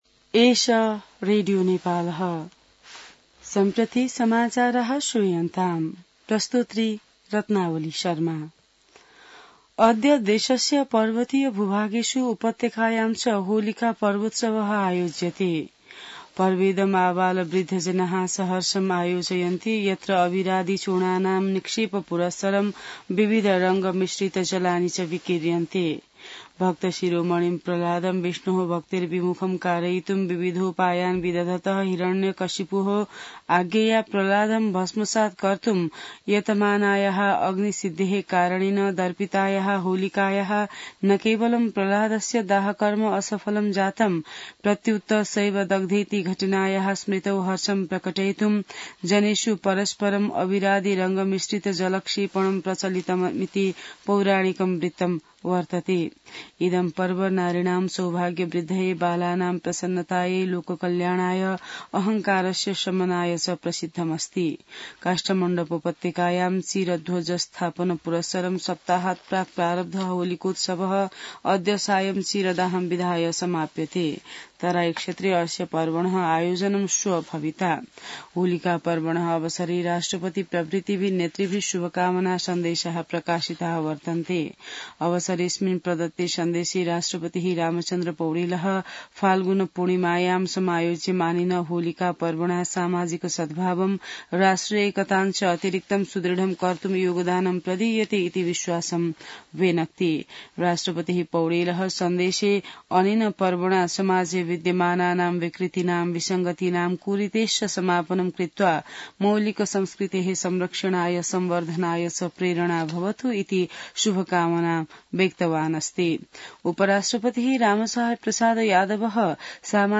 संस्कृत समाचार : ३० फागुन , २०८१